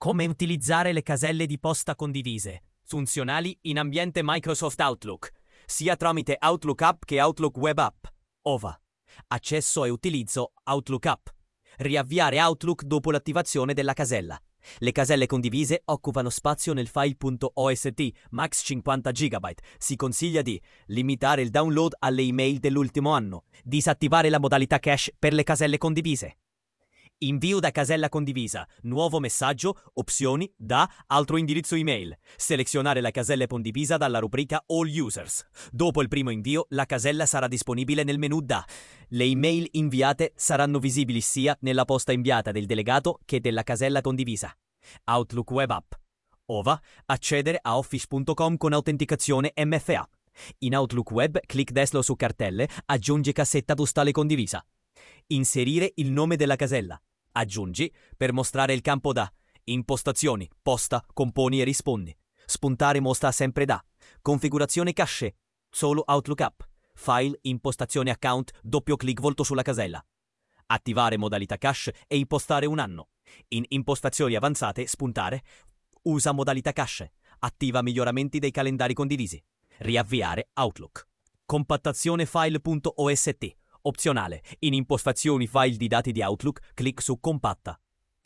Guida-Audio-per-luso-mail-condivise.mp3